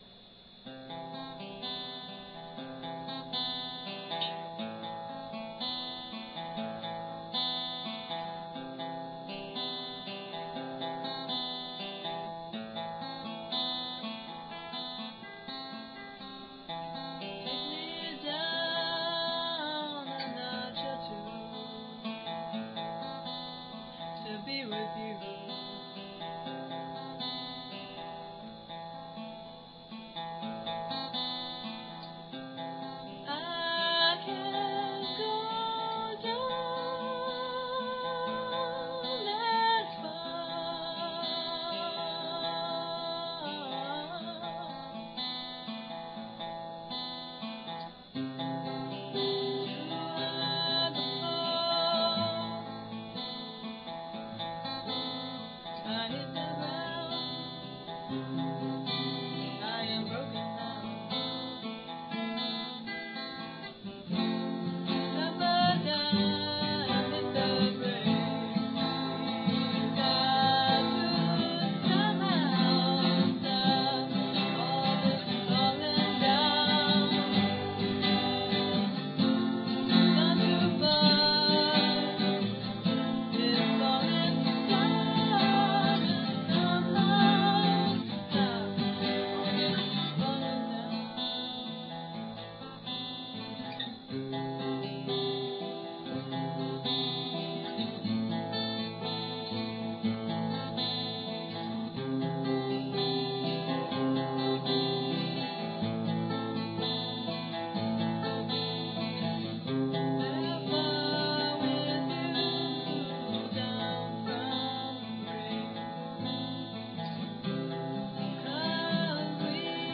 Yamaha 12-string and voice
Washburn acoustic guitar and voice